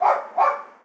dog_bark_small_04.wav